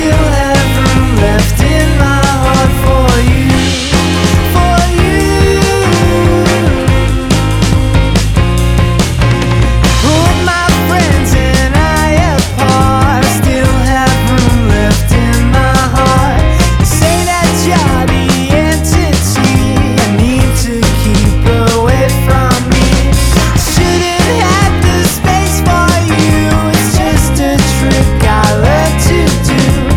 Жанр: Поп / Инди